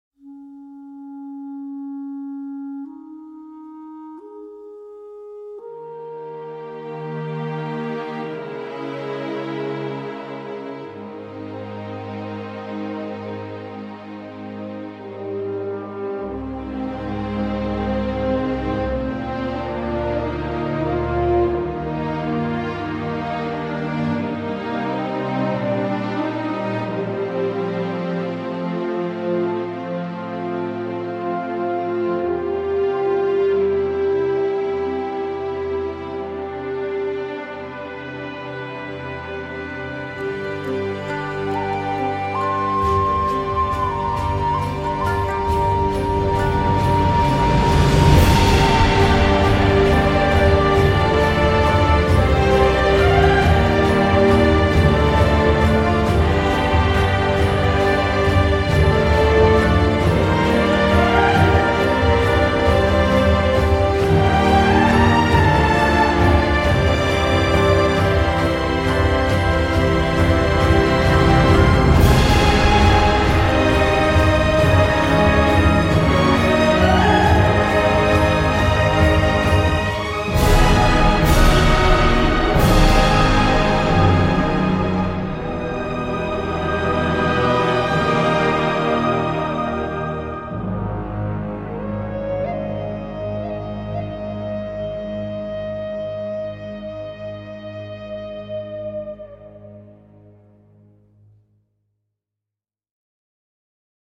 orchestre, chœur et sonorités asiatiques et moyen-orientales